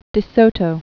(dĭ sōtō, dĕ), Hernando or Fernando 1496?-1542.